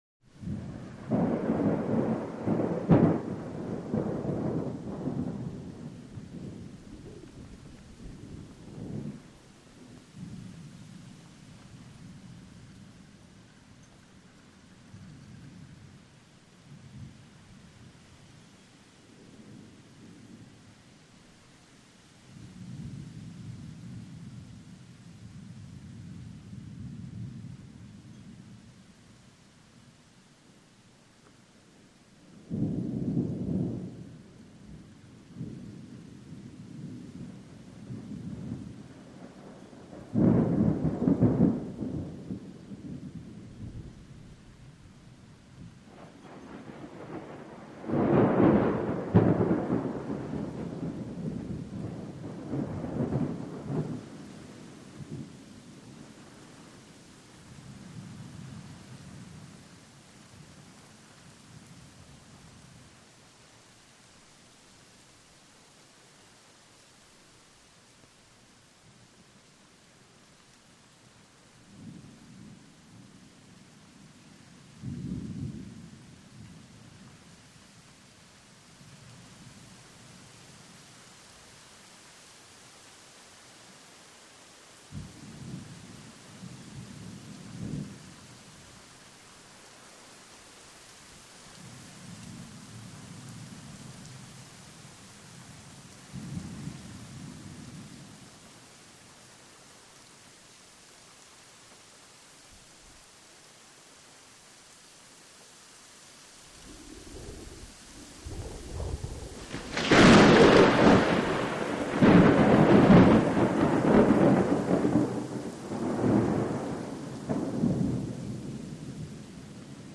Light Rain/Wind
Thunder; Rumbles And Cracks In The Distance With Light Rain Swells And Some Whirling Wind.